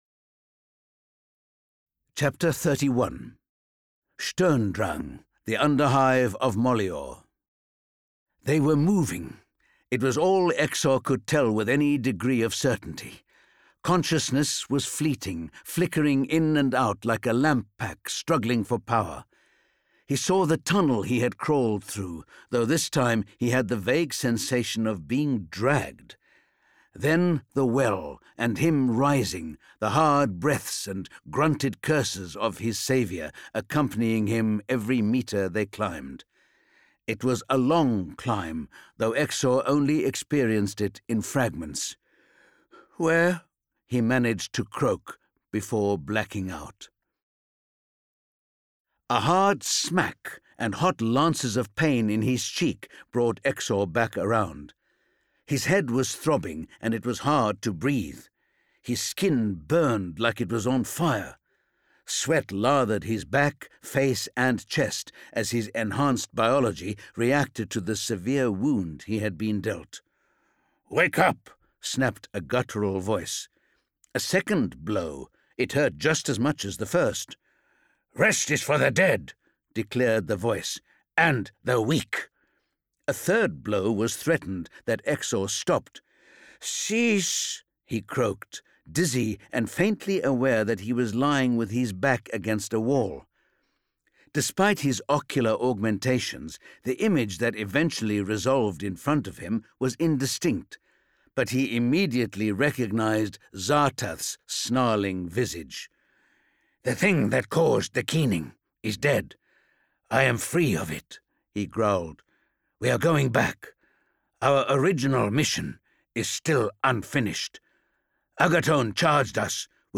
Index of /Games/MothTrove/Black Library/Warhammer 40,000/Audiobooks/Rebirth